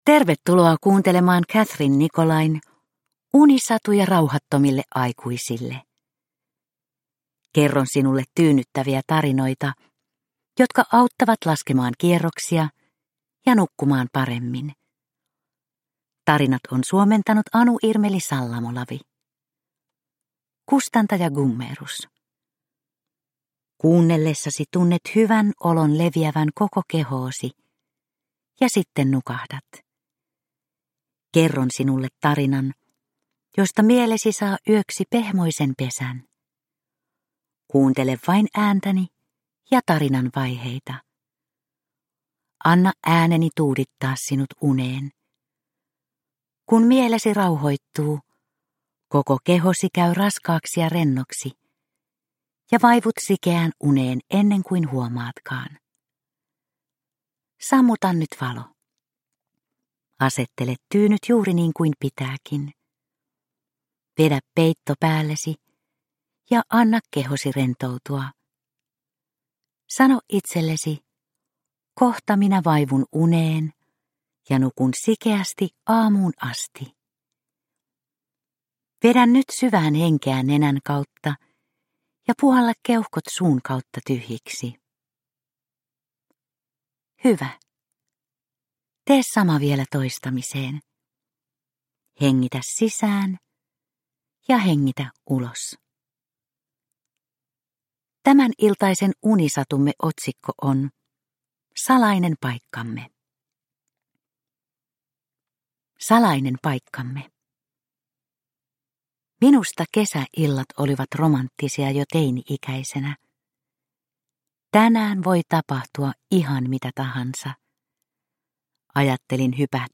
Unisatuja rauhattomille aikuisille 36 - Salainen paikkamme – Ljudbok – Laddas ner